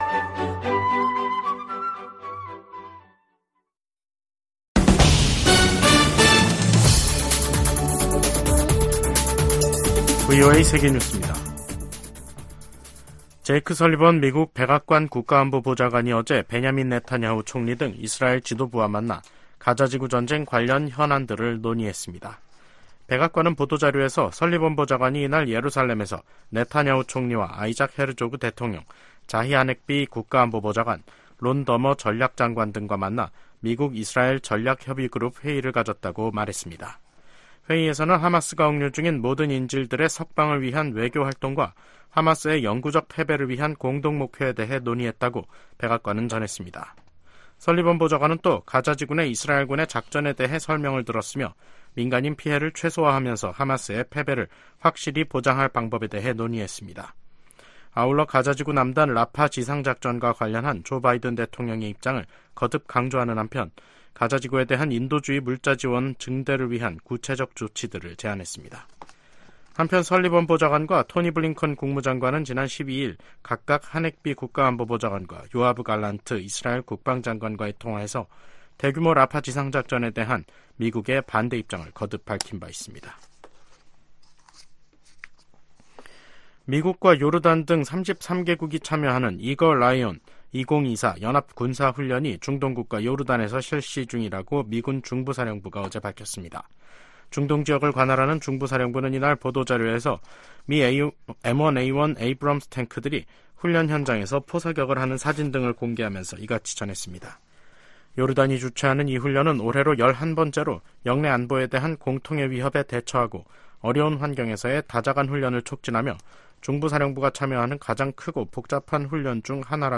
VOA 한국어 간판 뉴스 프로그램 '뉴스 투데이', 2024년 5월 20일 3부 방송입니다. 미국 국무부가 북한의 단거리 탄도미사일 발사를 규탄하며 거듭되는 북한 미사일 발사의 불법성을 지적했습니다. 북한과 러시아가 대량살상무기 관련 불법 금융활동 분야에서 가장 큰 위협국이라고 미국 재무부가 밝혔습니다. 미국, 한국, 일본의 협력 강화를 독려하는 결의안이 미국 하원 외교위원회를 통과했습니다.